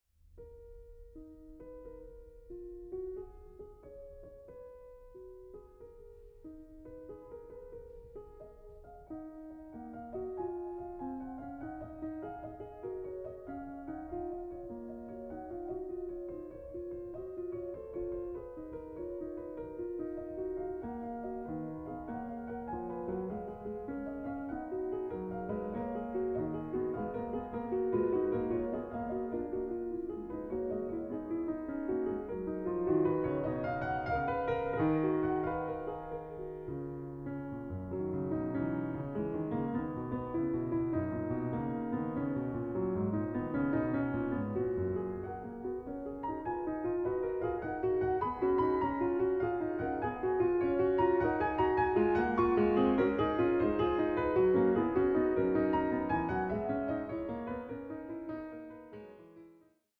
in E-Flat Minor: Prelude 4:58